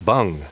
Prononciation du mot bung en anglais (fichier audio)
Prononciation du mot : bung